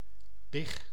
Ääntäminen
Synonyymit big van een everzwijn Ääntäminen Tuntematon aksentti: IPA: /bɪx/ Haettu sana löytyi näillä lähdekielillä: hollanti Käännös 1. prosiak {m} 2. prosię {n} Suku: n .